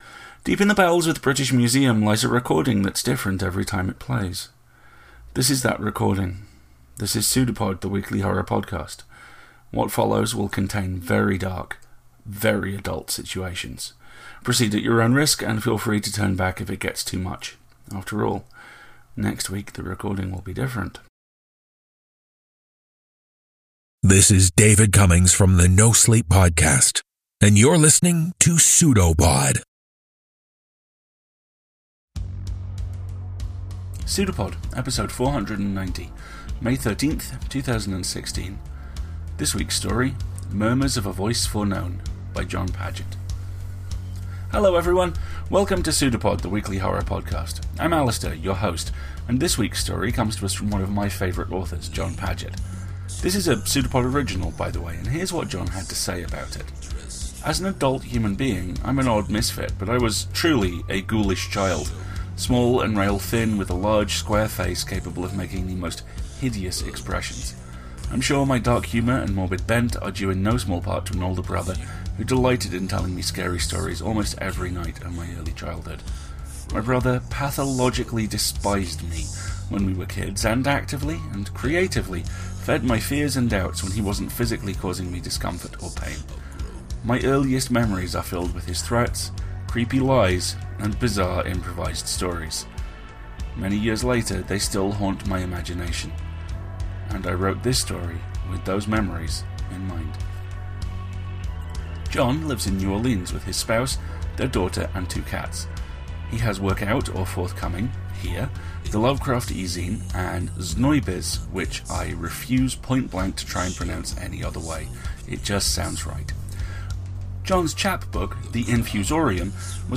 This is the audio short story of extended childhood sibling rivalry in which victim may be more unstable than realized…